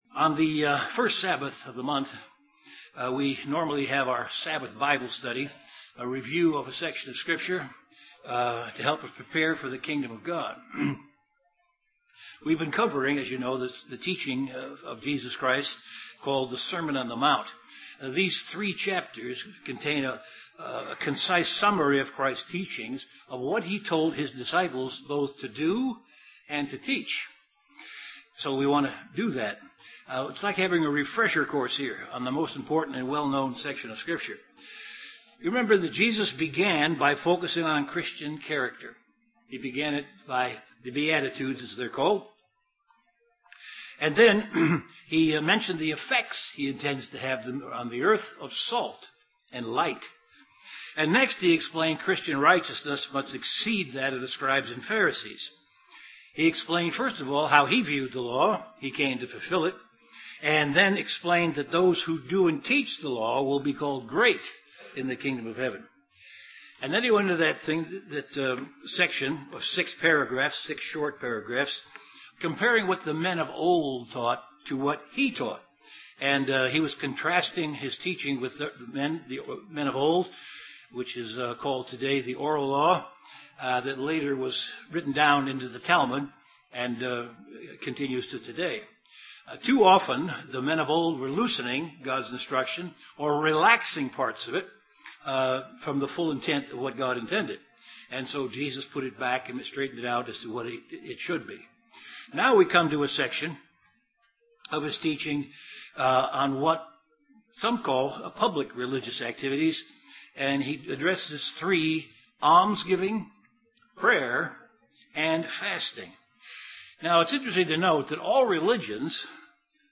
Continuation of an in-depth Bible Study on the Sermon on the Mount. This session discusses almsgiving and prayer.